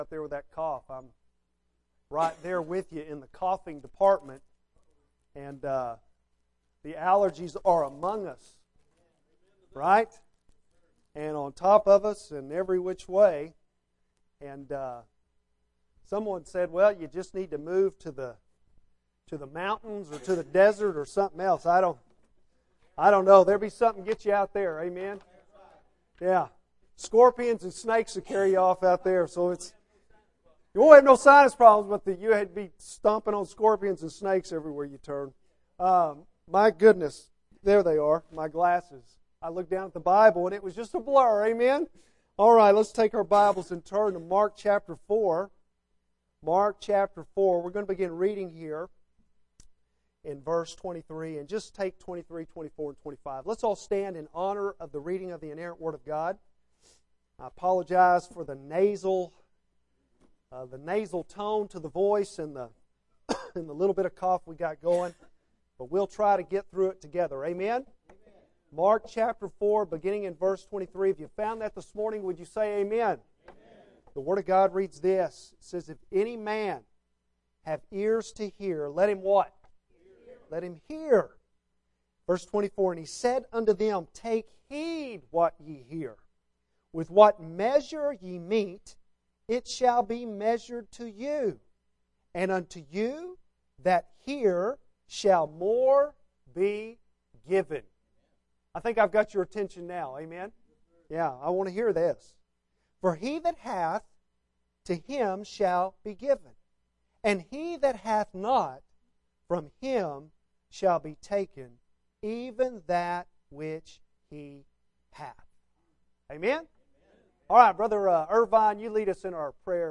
Apr 17 AM - New Hope Baptist Church